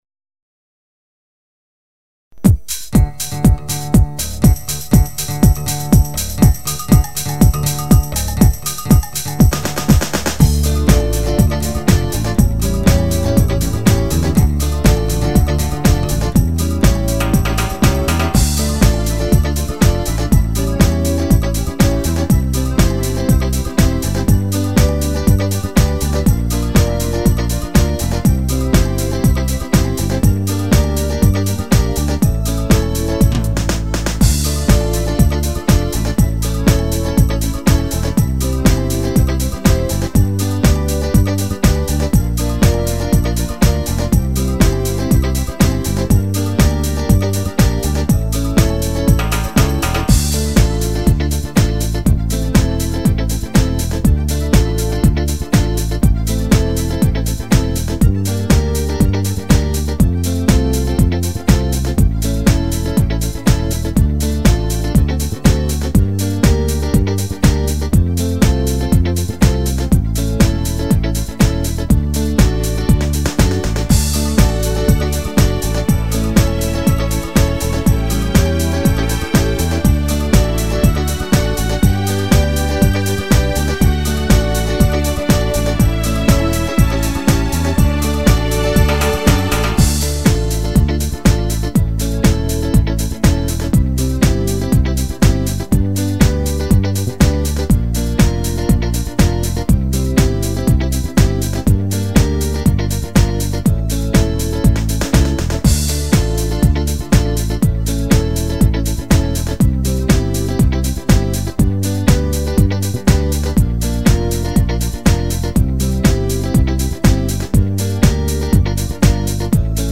Canzoni e musiche da ballo
Disco samba